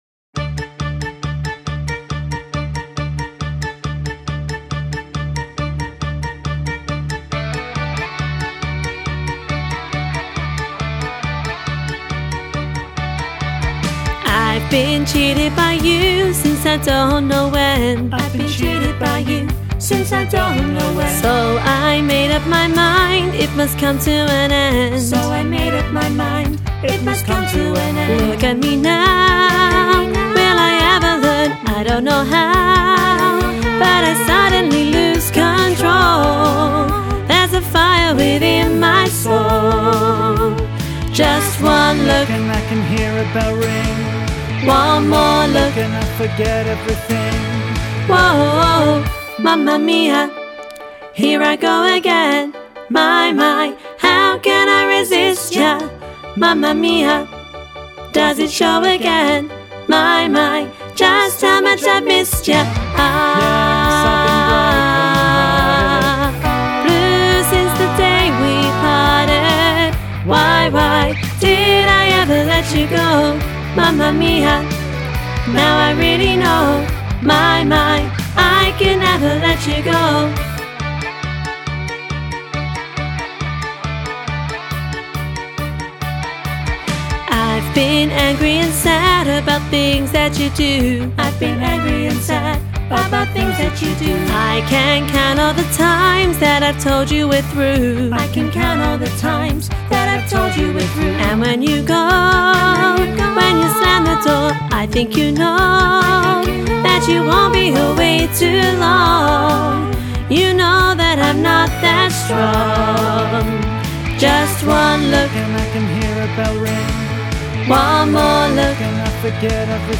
Training Tracks for Mamma Mia
mamma-mia-alto-half-mix.mp3